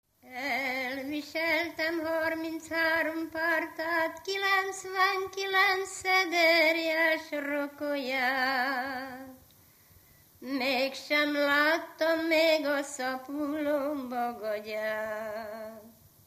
Erdély - Udvarhely vm. - Lövéte
ének
Stílus: 3. Pszalmodizáló stílusú dallamok
Szótagszám: 8.8.8.8
Kadencia: 5 (b3) 1 1